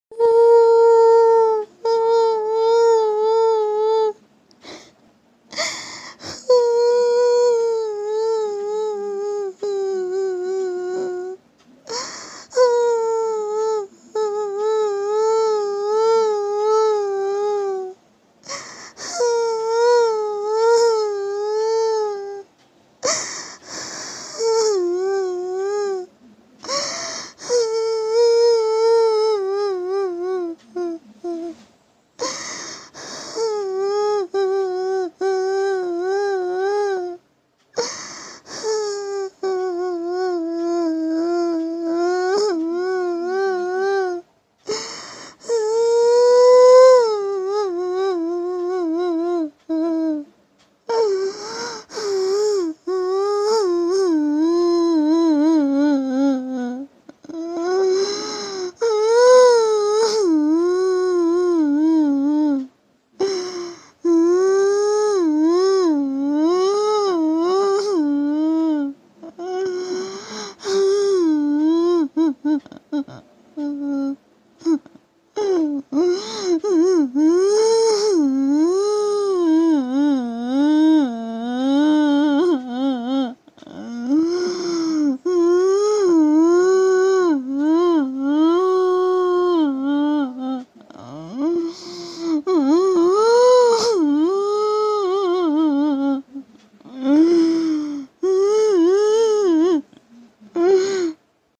Suara Wanita Menangis Tengah Malam
Kategori: Suara horor/ buas
suara-wanita-menangis-tengah-malam-id-www_tiengdong_com.mp3